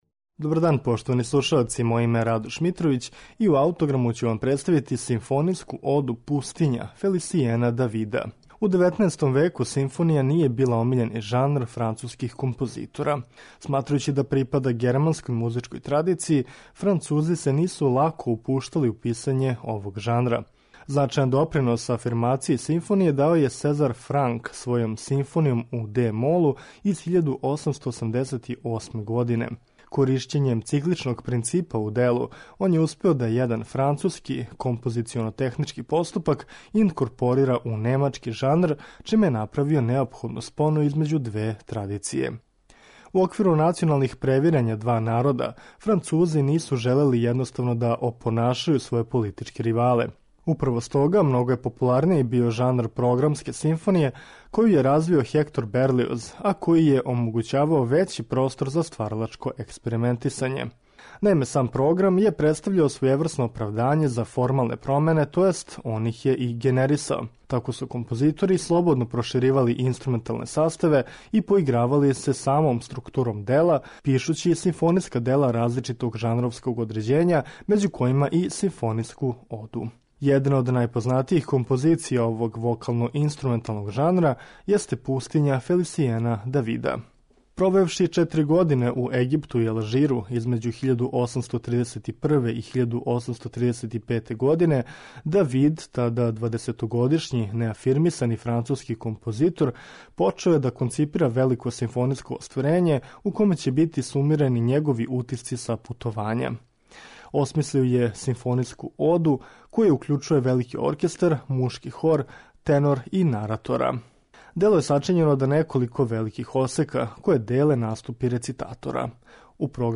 Фелисијен Давид - симфонијска ода "Пустиња"
Писано за велики симфонијски оркестар, мушки хор, тенора и наратора, ово дело сублимира ауторово лично искуство боравка у Египту и Алжиру.